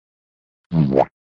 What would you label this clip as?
Womp Tags: Meme